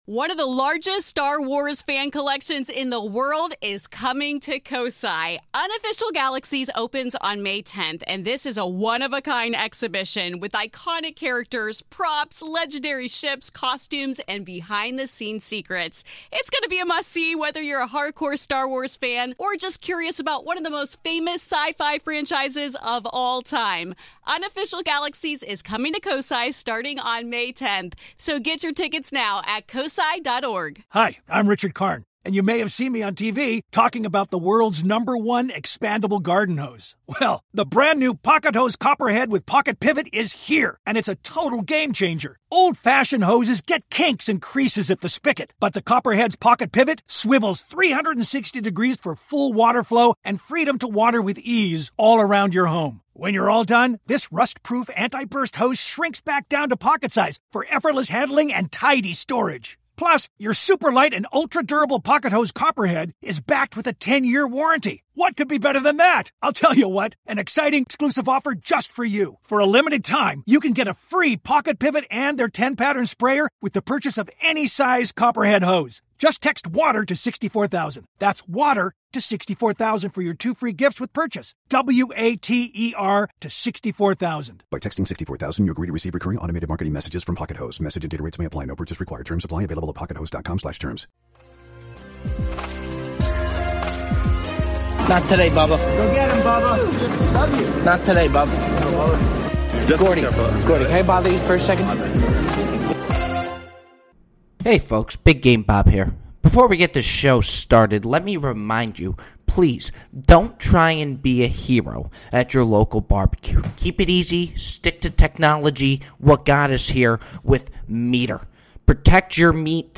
interview comedian and "Community" actor, Joel McHale.